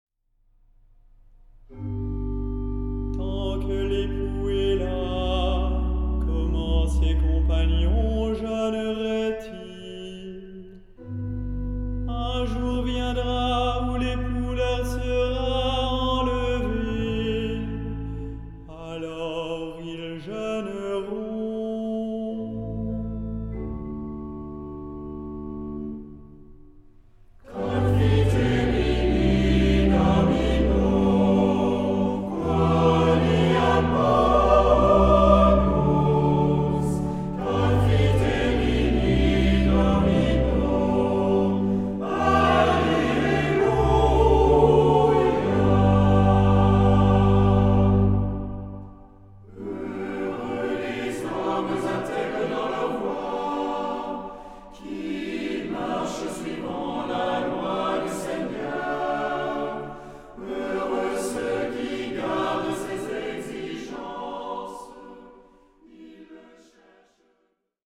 Genre-Stil-Form: Tropar ; Psalmodie
Charakter des Stückes: andächtig
Chorgattung: SATB  (4 gemischter Chor Stimmen )
Instrumente: Orgel (1) ; Melodieinstrument (1)
Tonart(en): G-Dur